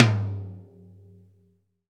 TOM TOM 95.wav